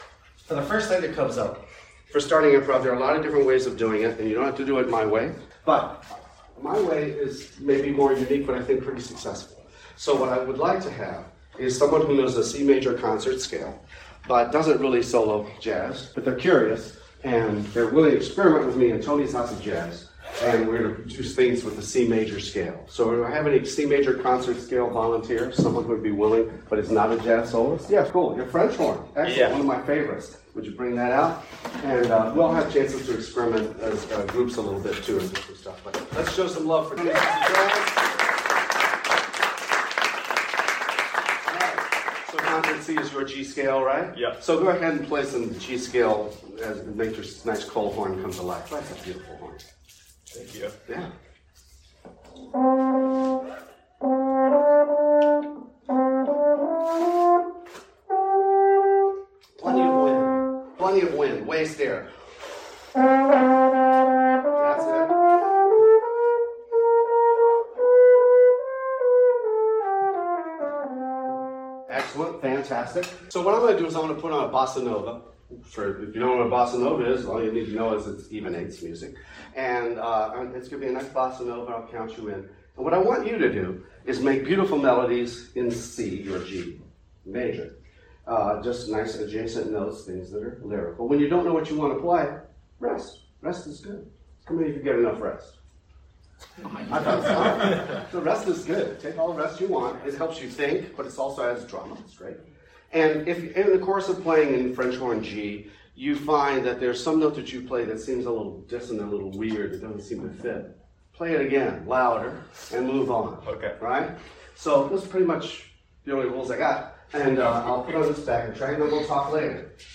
Hear a half-hour audio recording where I share this concept with music educators!